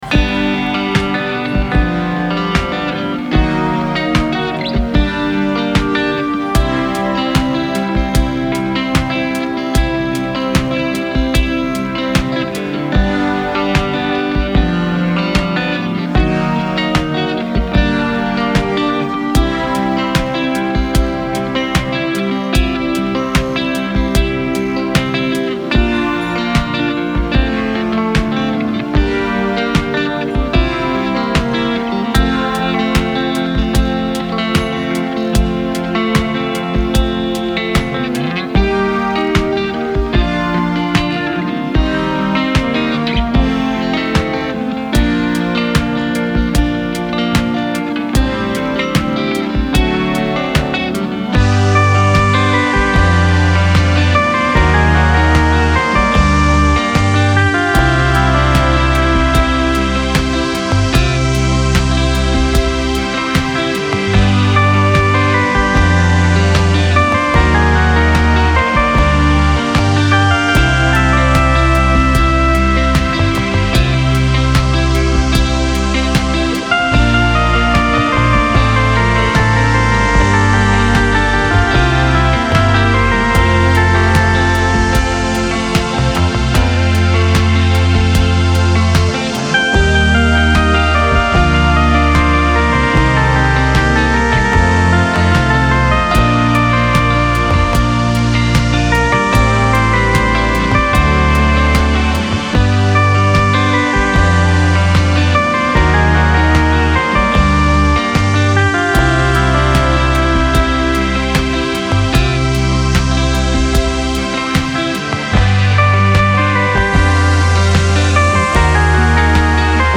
Genre : Experimental